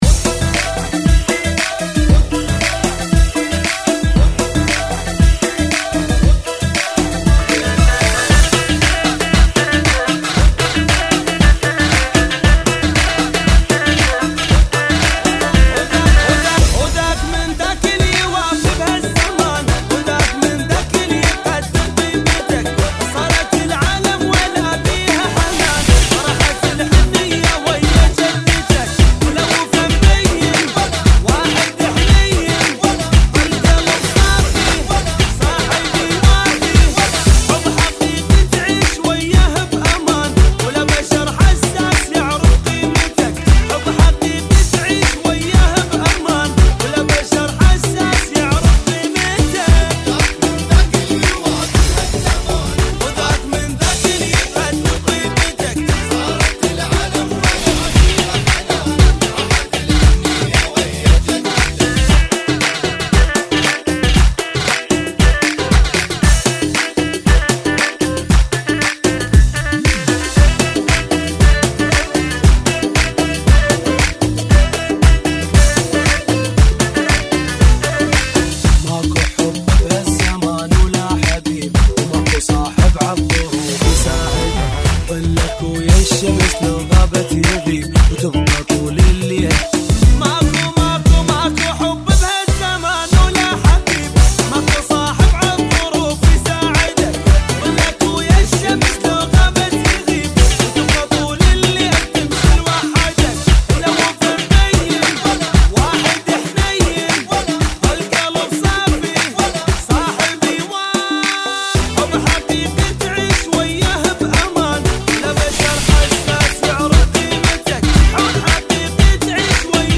Funky [ 116 Bpm ]